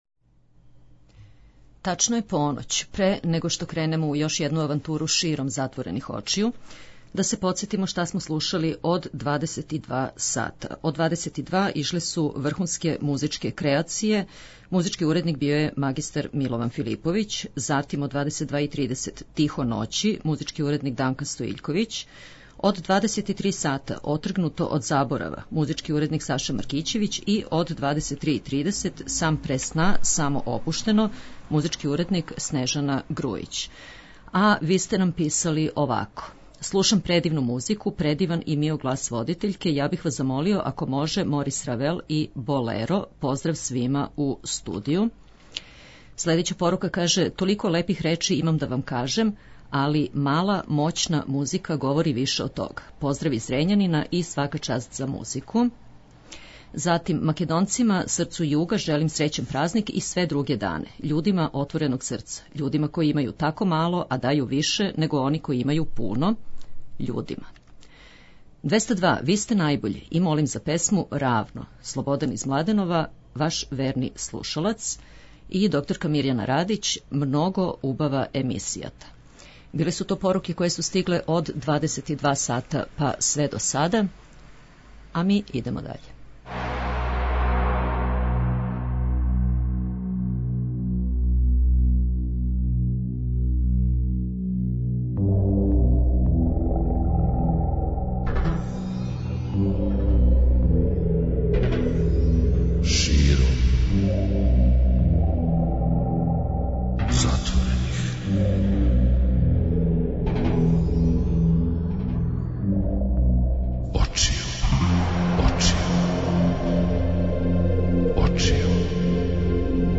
И ове ноћи преслушавамо најлепше женске и мушке вокале са домаће и светске сцене, од поноћи све до раних јутарњих сати.
преузми : 57.18 MB Широм затворених очију Autor: Београд 202 Ноћни програм Београда 202 [ детаљније ] Све епизоде серијала Београд 202 We care about disco!!!